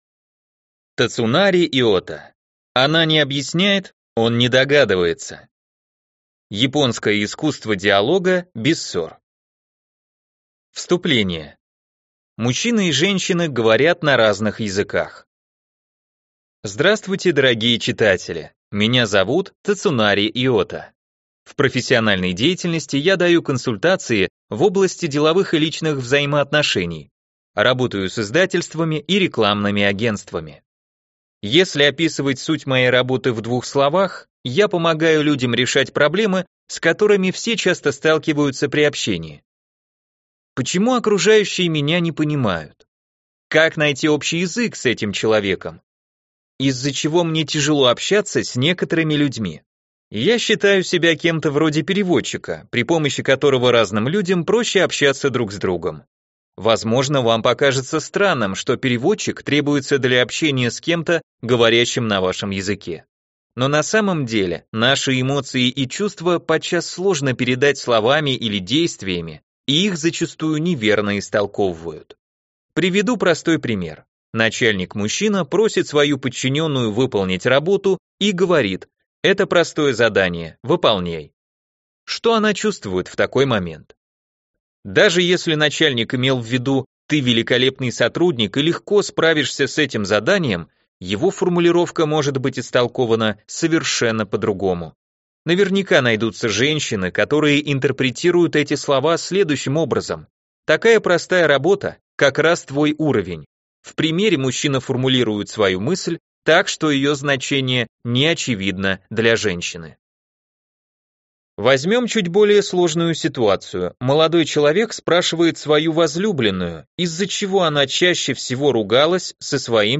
Аудиокнига Она не объясняет, он не догадывается. Японское искусство диалога без ссор | Библиотека аудиокниг